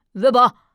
c01_5胖小孩_1.wav